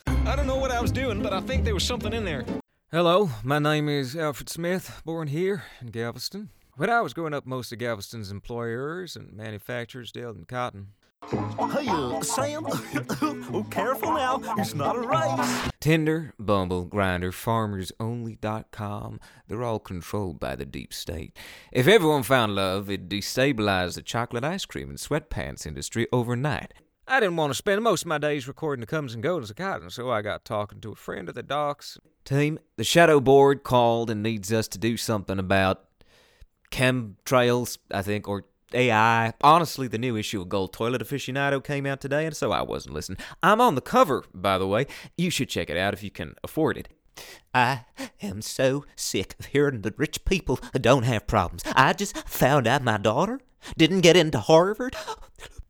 southern us | character
Southern_American_Mixed_Samples3.mp3